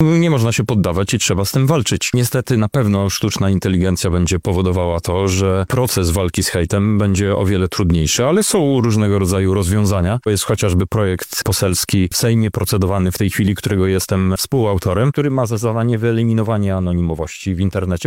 W sobotę, 8 marca na Wydziale Politologii i Dziennikarstwa UMCS odbyła się debata na temat hejtu w sieci, w dobie rozwoju sztucznej inteligencji.
– mówi Krzysztof Hetman, poseł Parlamentu Europejskiego.